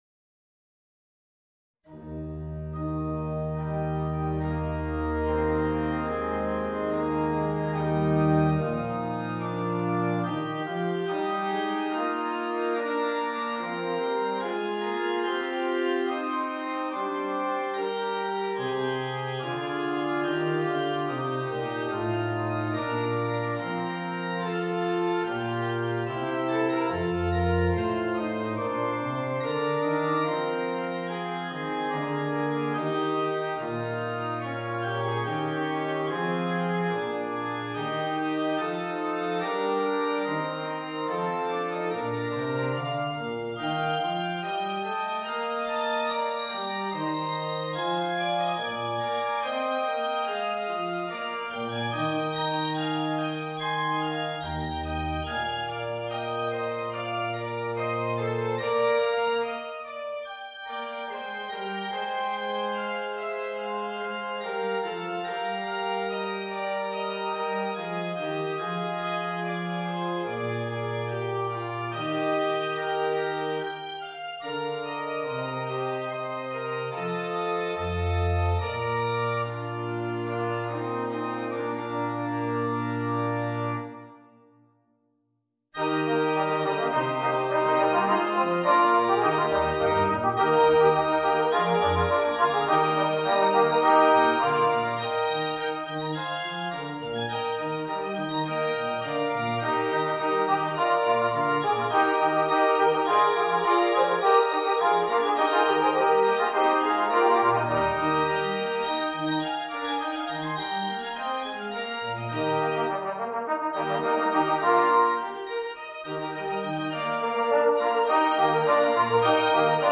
Voicing: 2 Alto Trombones and Organ